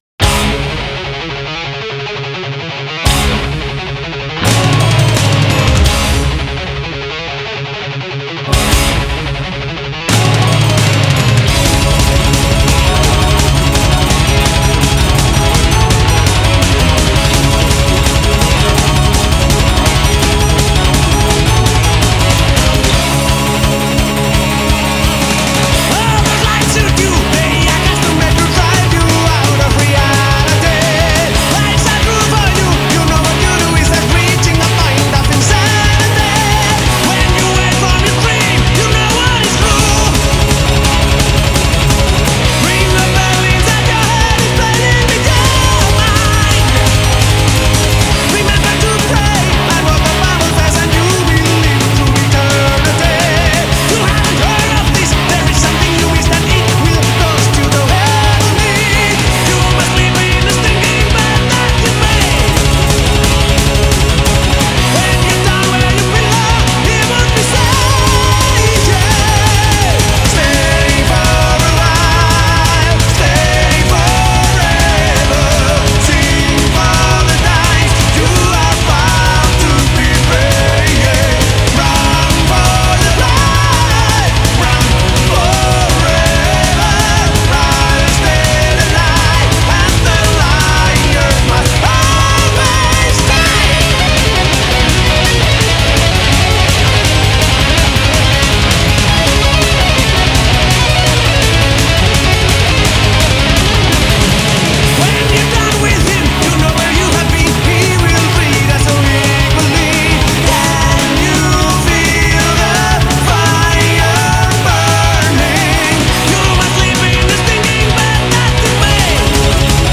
на HELLOWEEN похоже. крутой движняк!